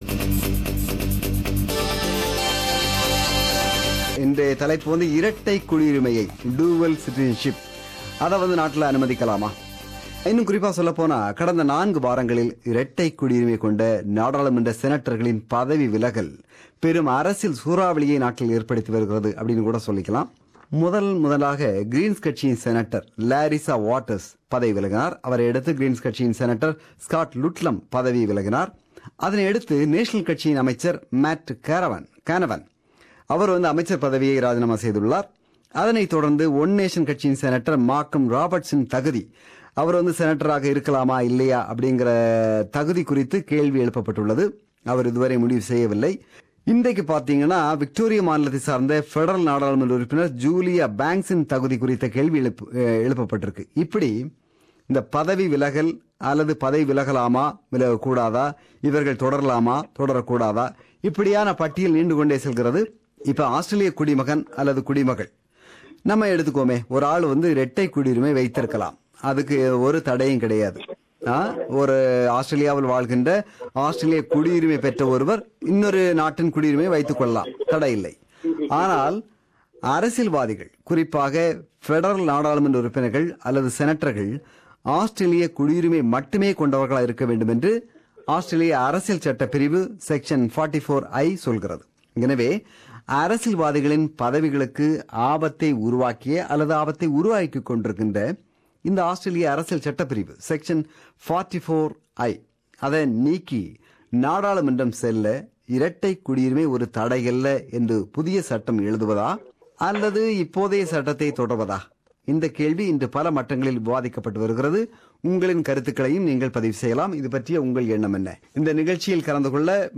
This is the compilation of opinions expressed by our listeners who participated in Talkback (Vanga Pesalam) program on 28 July 2017.